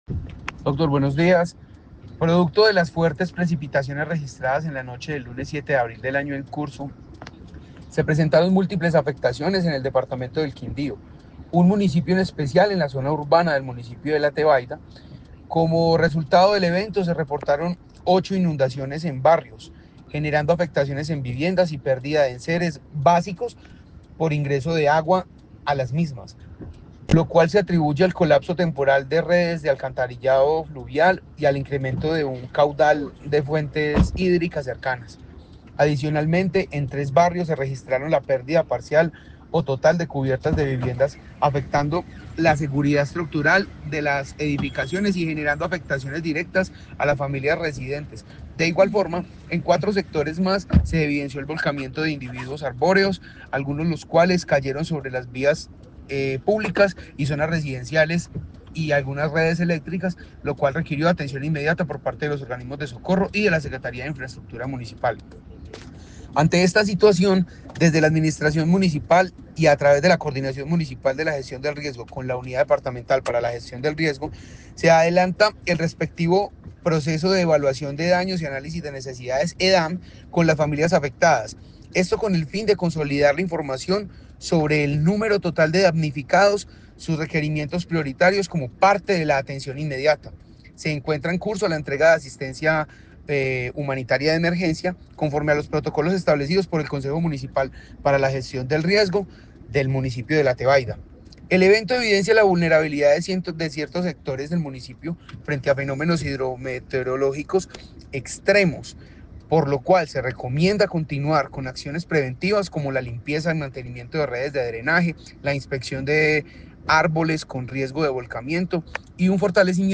Jaider Hidalgo, director riesgo, Quindío